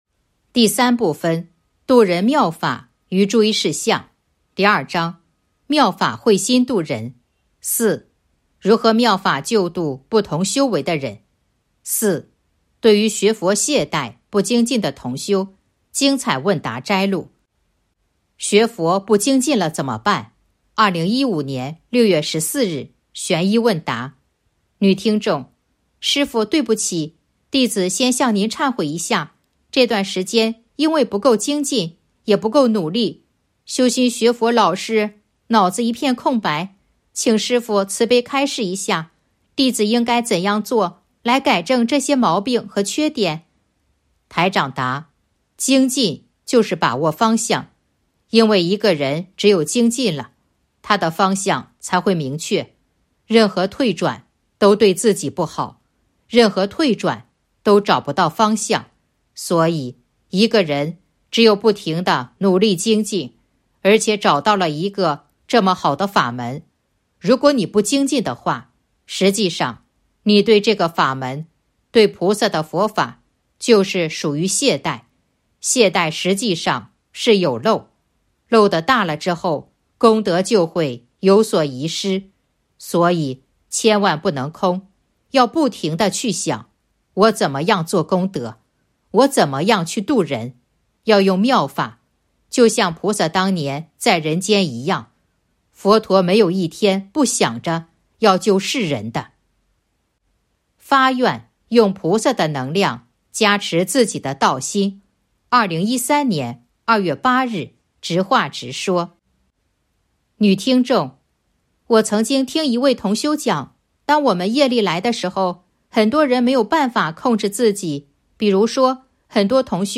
029.（四）对于学佛懈怠不精进的同修精彩问答摘录《弘法度人手册》【有声书】